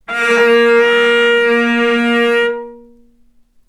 vc_sp-A#3-ff.AIF